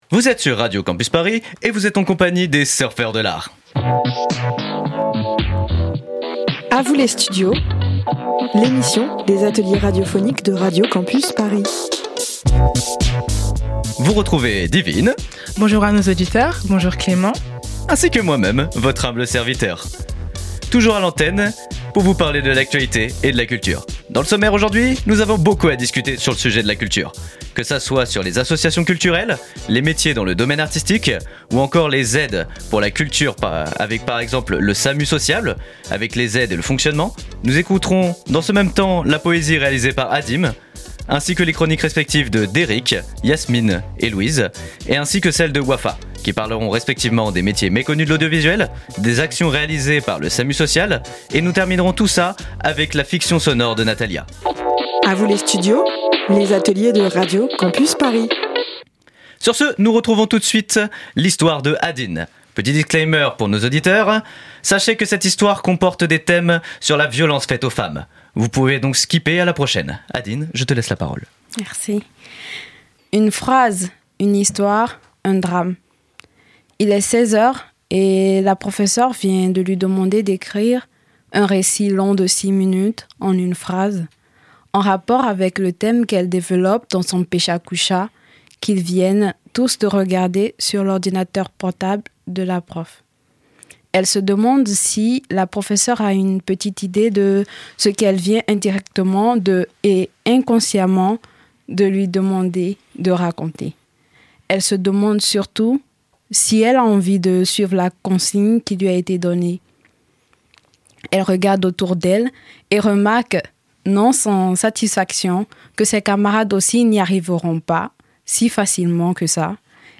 Les étudiant·es de Paris 8 vous invitent à découvrir Surfer des Arts — une émission qui mêle poésie, interview, chroniques et fiction sonore.